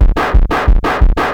Index of /90_sSampleCDs/USB Soundscan vol.01 - Hard & Loud Techno [AKAI] 1CD/Partition A/01-180TBEAT